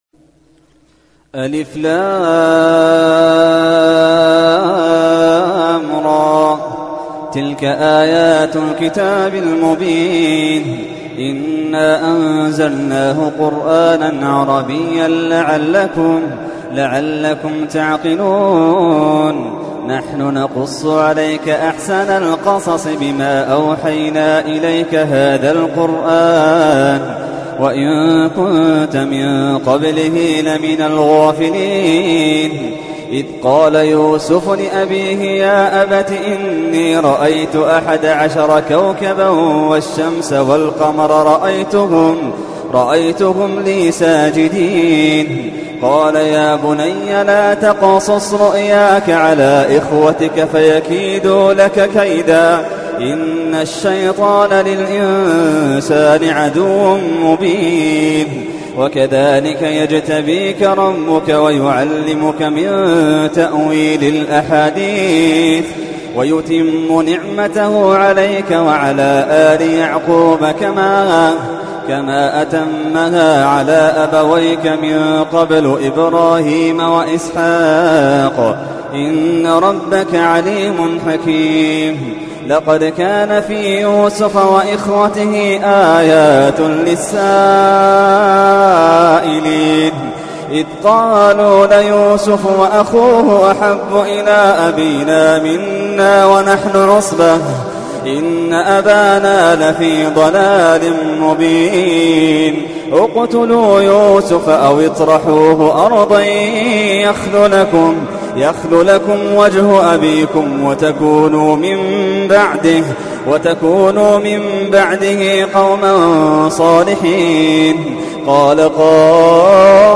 تحميل : 12. سورة يوسف / القارئ محمد اللحيدان / القرآن الكريم / موقع يا حسين